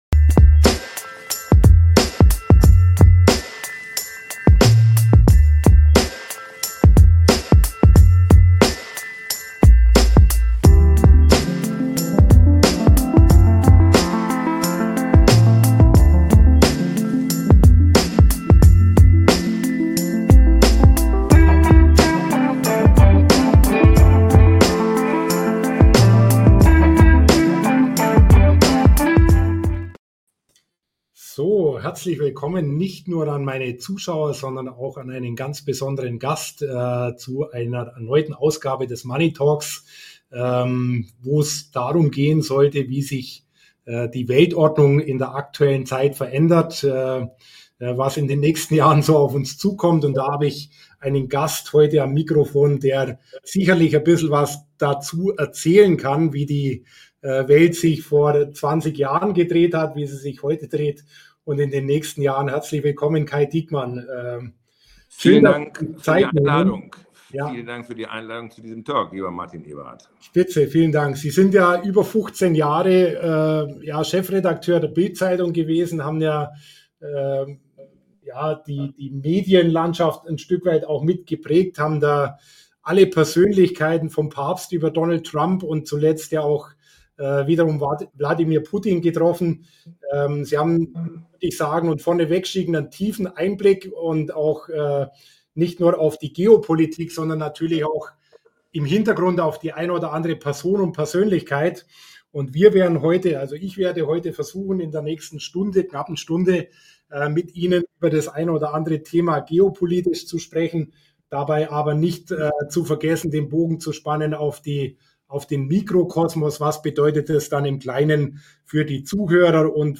Ein Gespräch über die großen Linien von Politik, Wirtschaft und Medien – mit historischem Rückblick und kritischem Blick nach vorn.